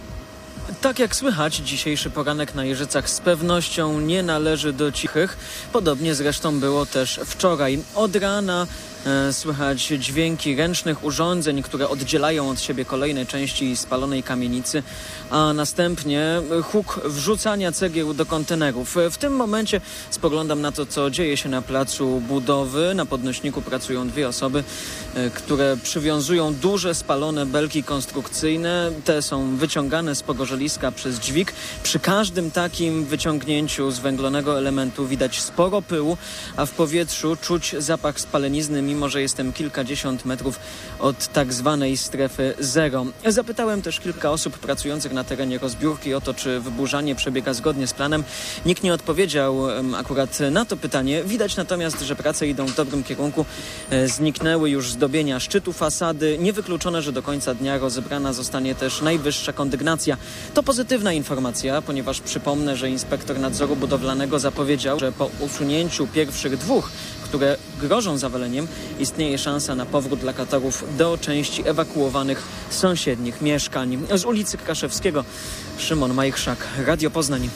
ko4una80ds0z1ni_live_wyburzanie.mp3